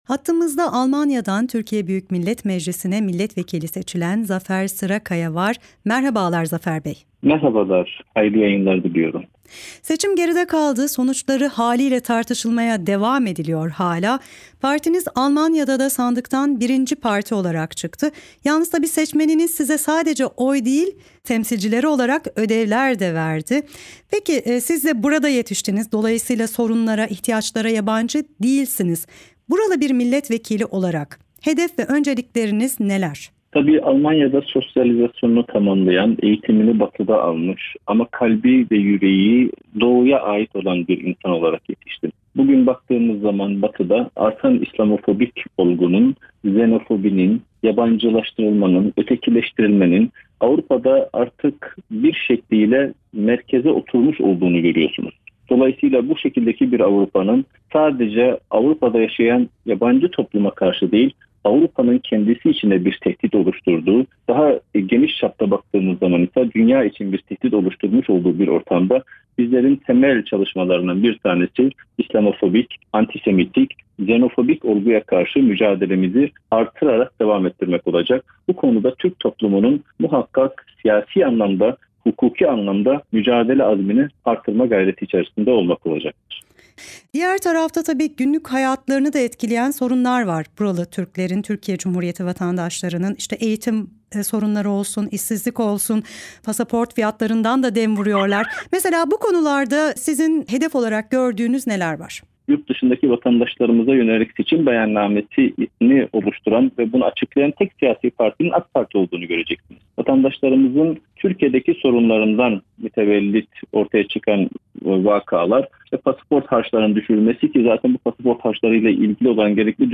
Audio: Zafer Sırakaya ile özel söyleşi